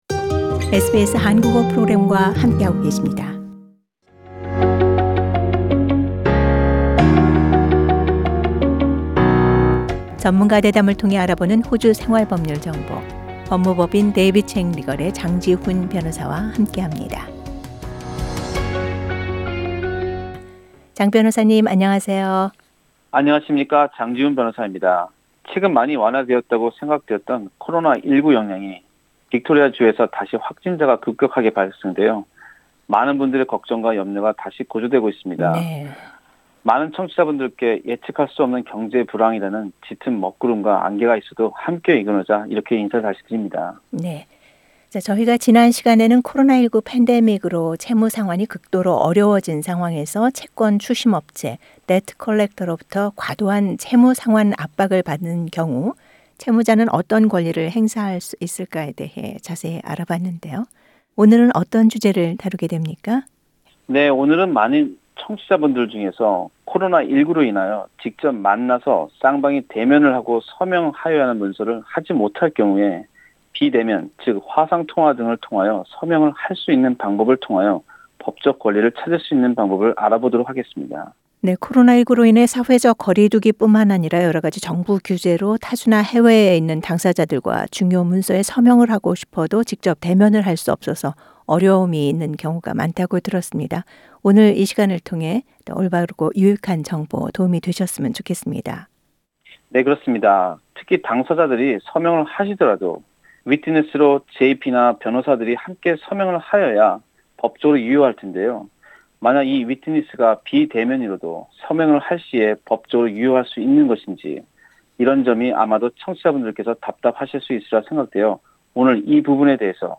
전문가 대담을 통해 알아보는 호주 생활법률정보